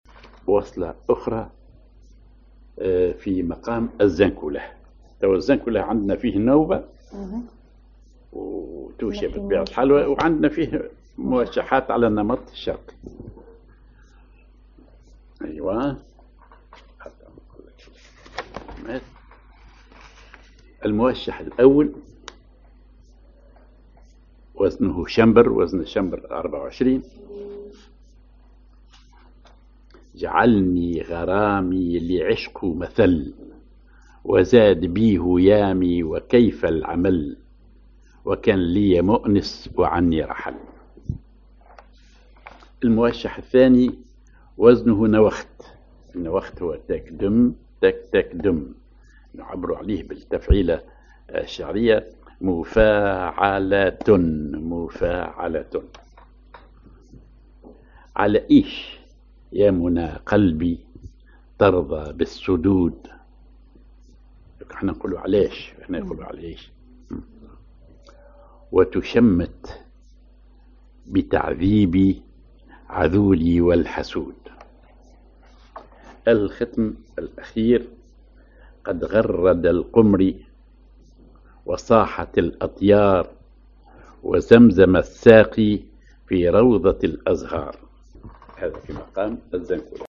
Maqam ar زنكلاه
Rhythm ID سماعي ثقيل
genre سماعي